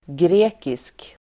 Uttal: [gr'e:kisk]
grekisk.mp3